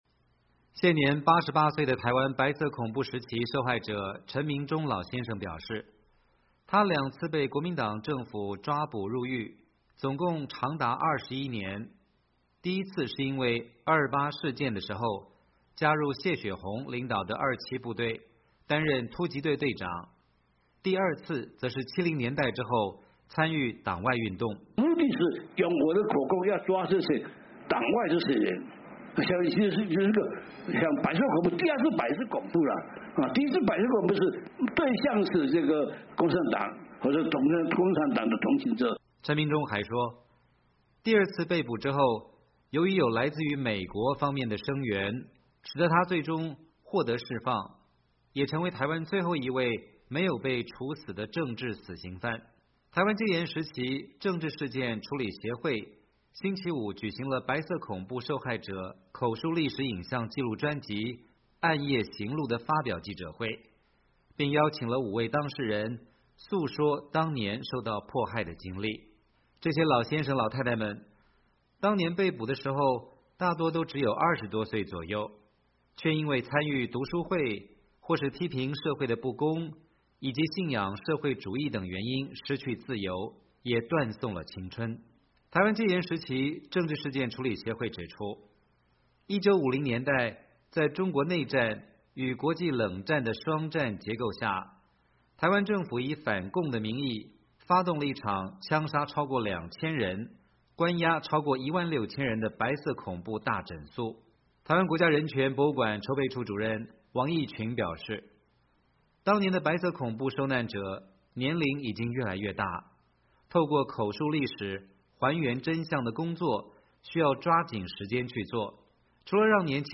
台湾戒严时期政治事件处理协会星期五举行了白色恐怖受害者口述历史影像记录专辑“暗夜行路”的发表记者会，并邀请了5位当事人诉说当年受到迫害的经历。
台湾无党籍立委高金素梅接受美国之音采访表示，台湾民进党政府近来有意推动保防法或是反渗透法，让人忧心是否反共戒严体制将悄然复辟。
执政党民进党立委陈亭妃告诉美国之音，白色恐怖与反渗透法完全是两回事，没有重回白色恐怖的问题。